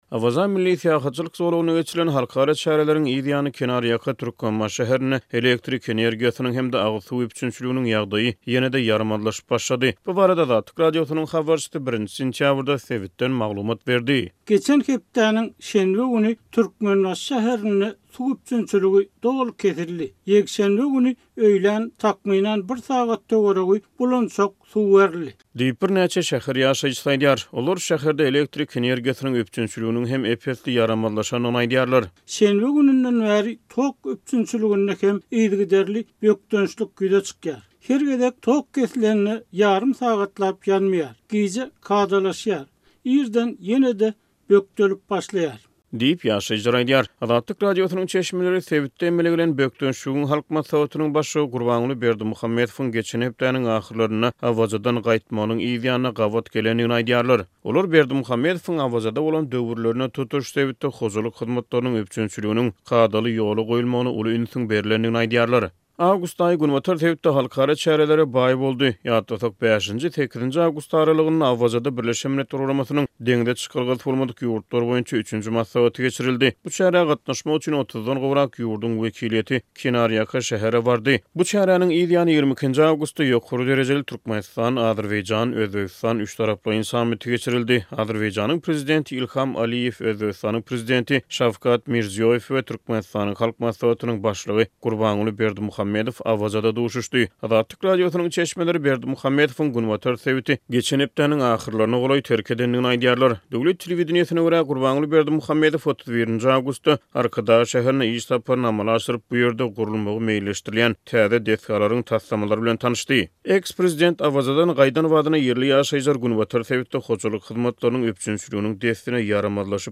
Awaza milli syýahatçylyk zolagynda geçirilen halkara çäreleriň yz ýany, kenar ýaka Türkmenbaşy şäherinde elektrik energiýasynyň hem-de agyz suw üpjünçiliginiň ýagdaýy ýene-de ýaramazlaşyp başlady. Bu barada Azatlyk Radiosynyň habarçysy 1-nji sentýabrda sebitden maglumat berdi.